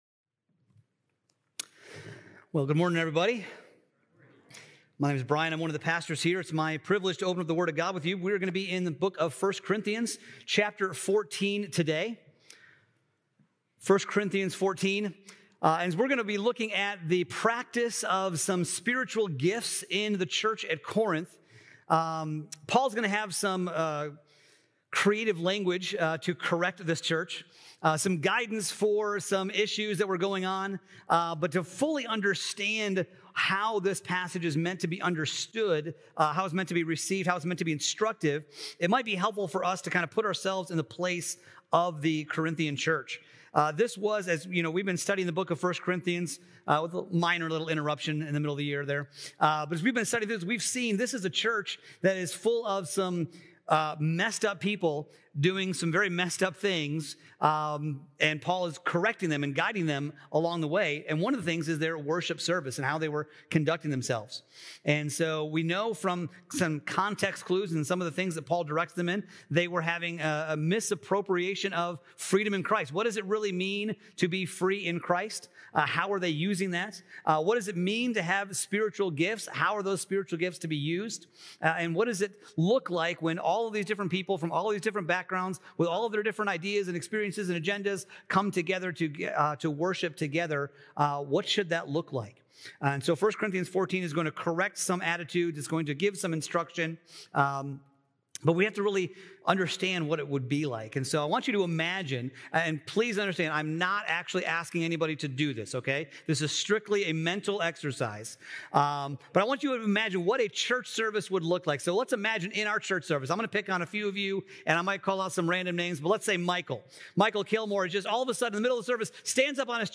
July 26th Sermon